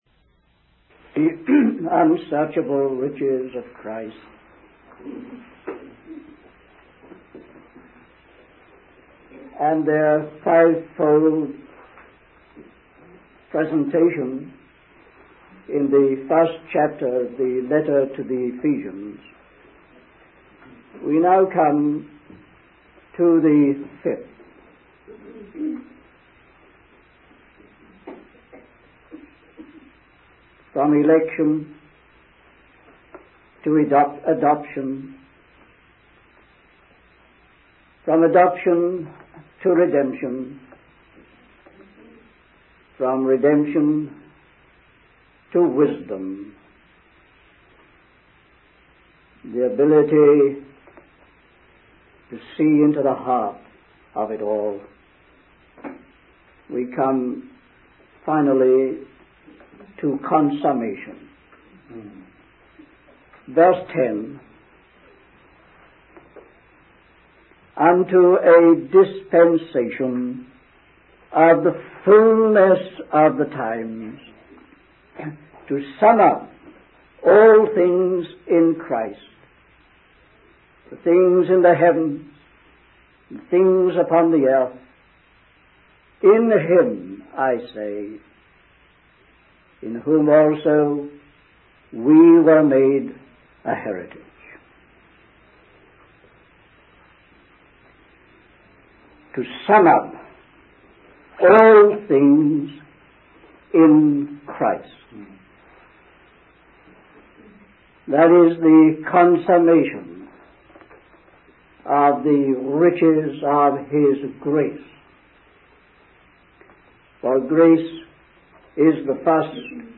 In this sermon, the speaker addresses the conflicts and problems that arise among Christians.